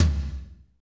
TOM F S L0SR.wav